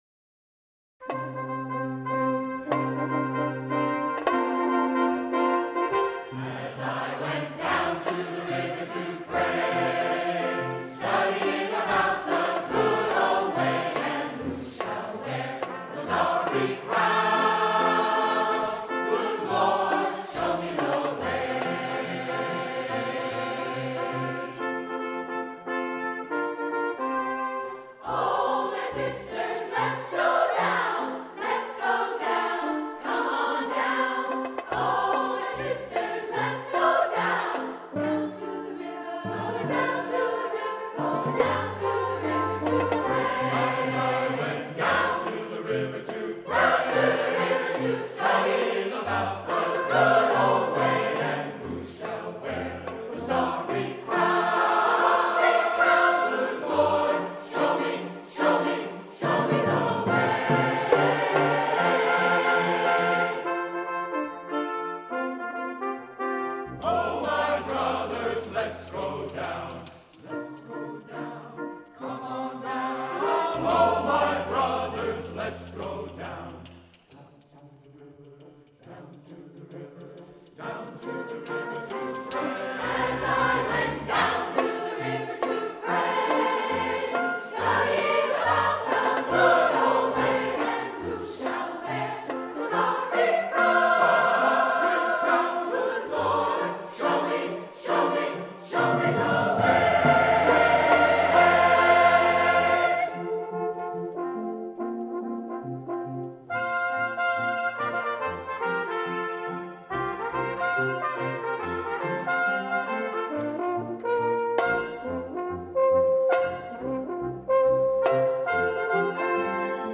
for SATB Chorus and Piano (2015)